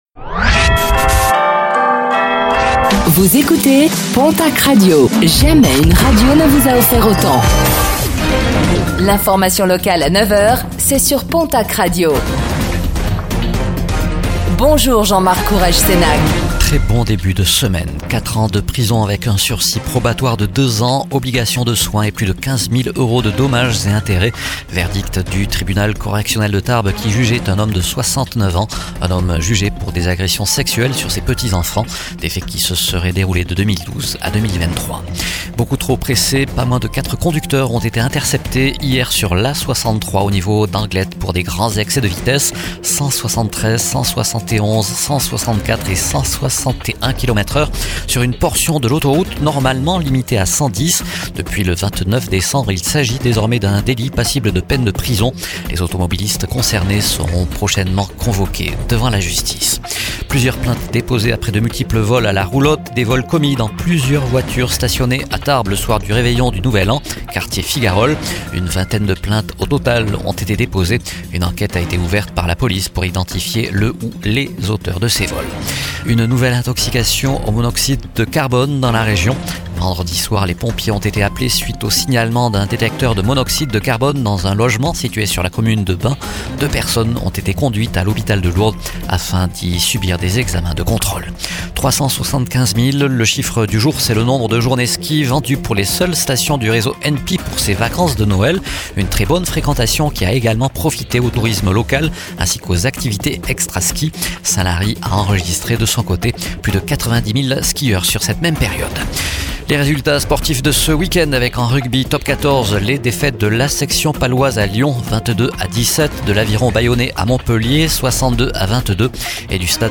09:05 Écouter le podcast Télécharger le podcast Réécoutez le flash d'information locale de ce lundi 05 janvier 2026